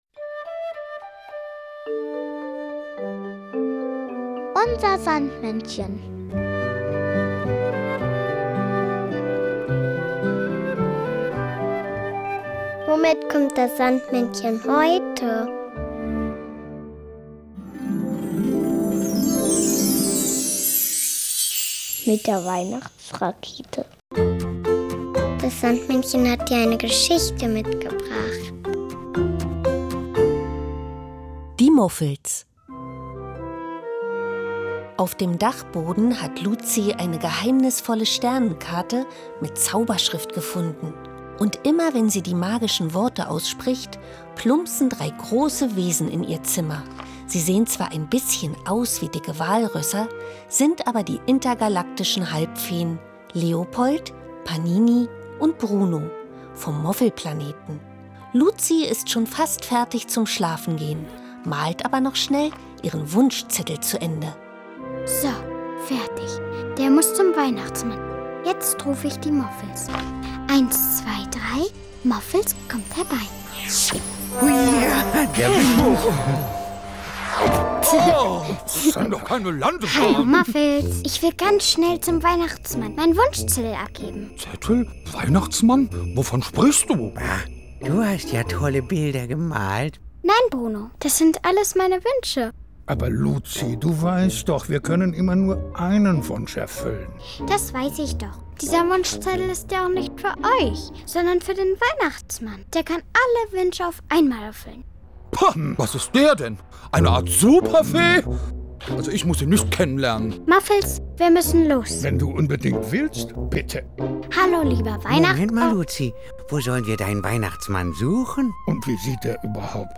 sondern auch noch das Weihnachtslied "Wünsche" von Kunterbuntd.